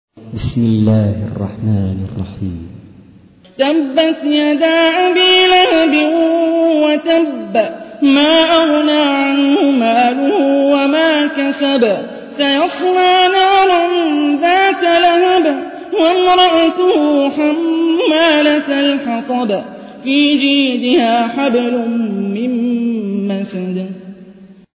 تحميل : 111. سورة المسد / القارئ هاني الرفاعي / القرآن الكريم / موقع يا حسين